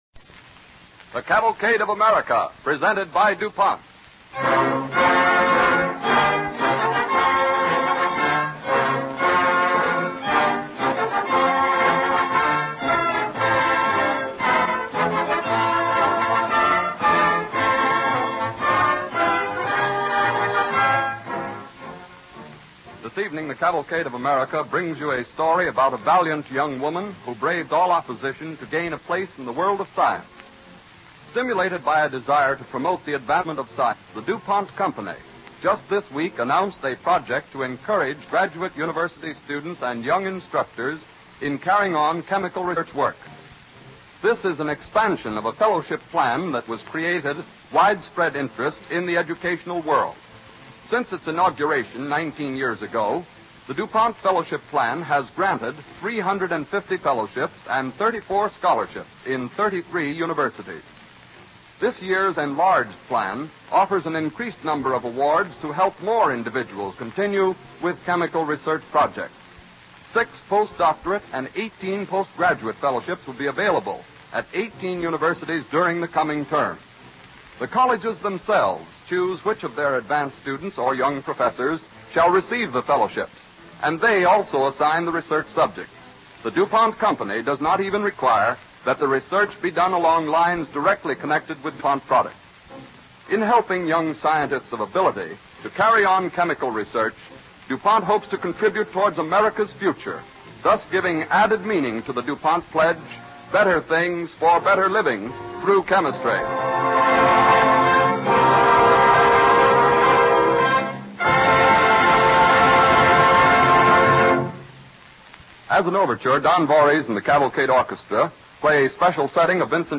With announcer Dwight Weist